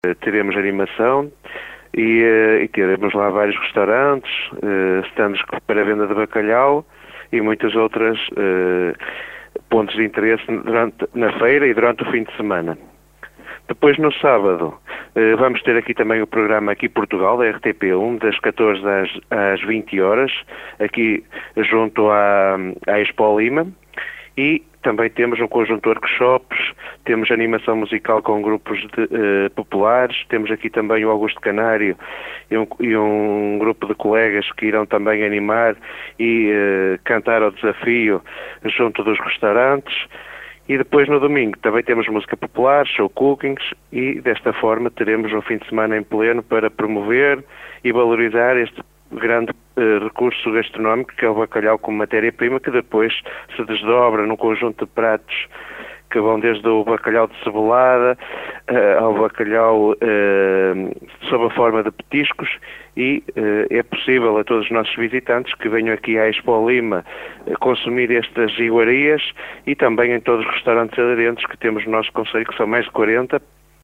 A aposta no turismo gastronómico continua a ser um das principais atracções do concelho de Ponte de Lima, como destaca o vereador do Turismo Paulo Sousa.